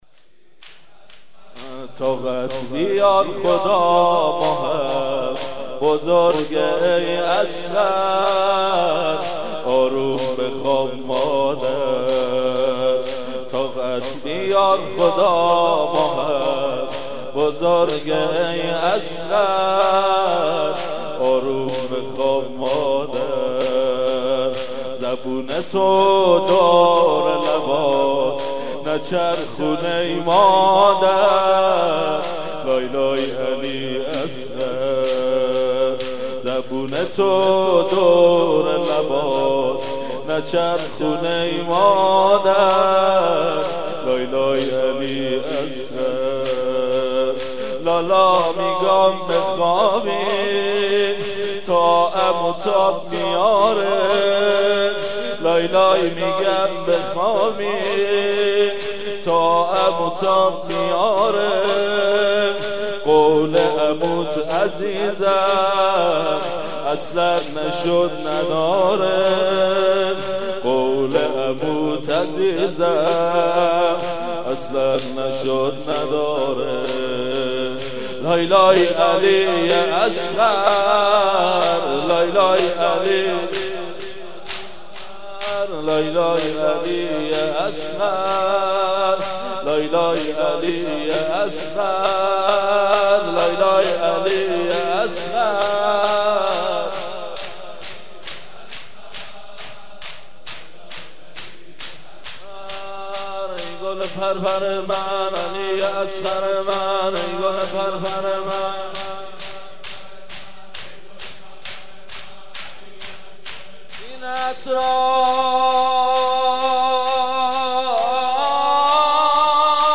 03.sineh zani.mp3